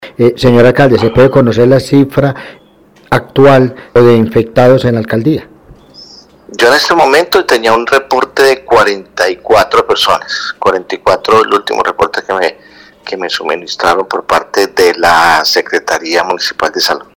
Periodismo Investigativo dialogó con el Alcalde de Armenia Dr. José Manuel Ríos Morales y sobre su salud explicó que: